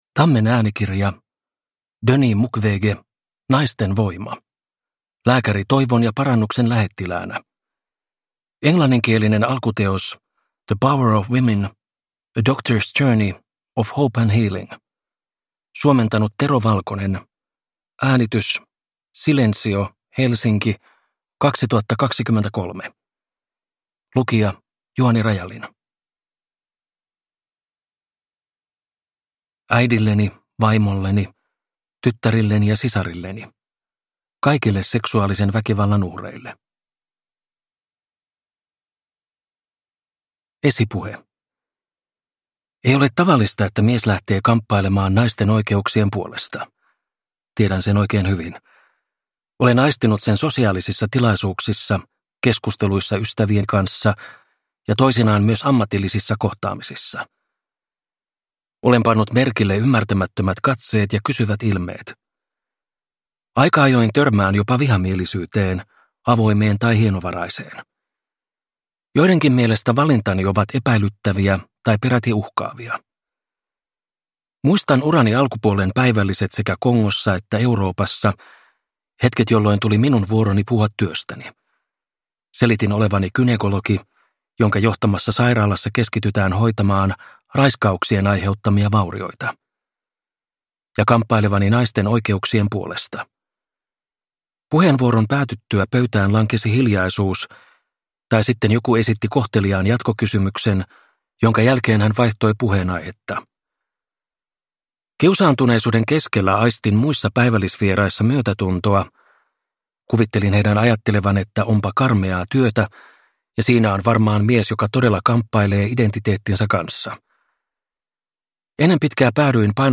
Naisten voima – Ljudbok – Laddas ner